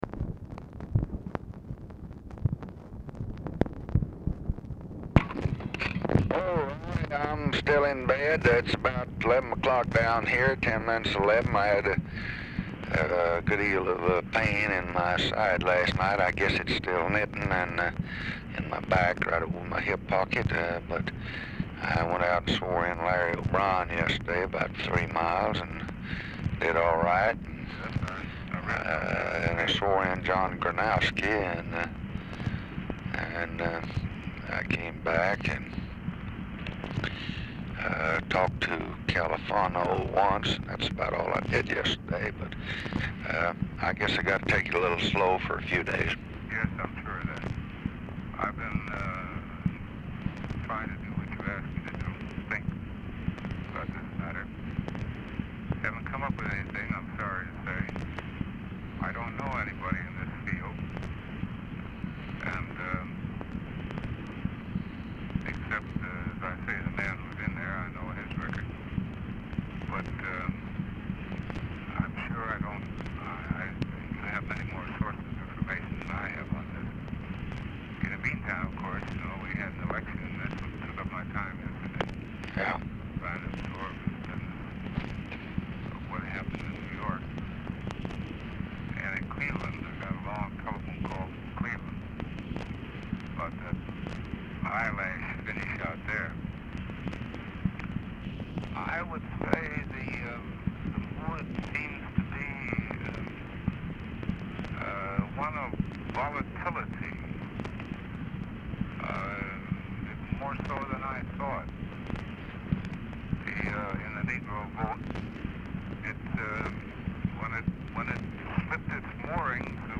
RECORDING STARTS AFTER CONVERSATION HAS BEGUN; POOR SOUND QUALITY AT TIMES;
Format Dictation belt
Location Of Speaker 1 LBJ Ranch, near Stonewall, Texas
Specific Item Type Telephone conversation